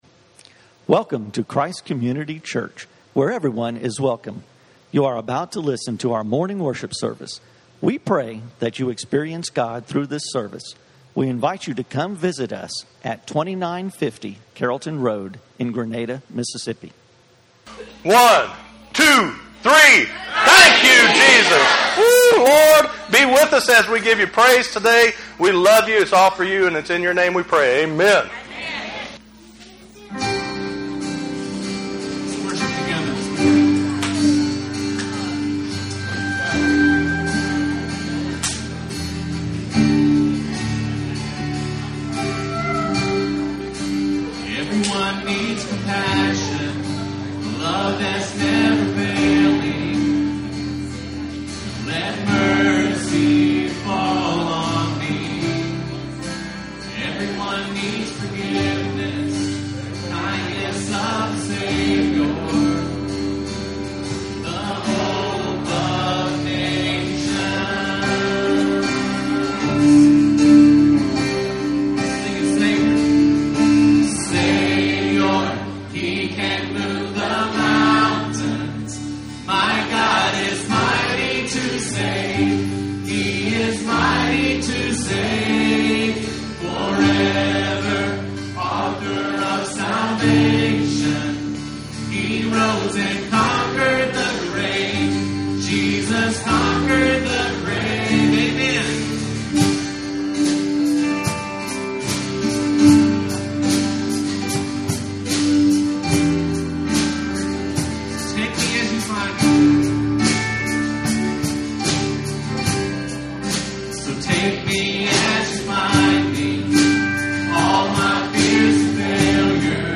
Christ Community On Mission - Messages from Christ Community Church.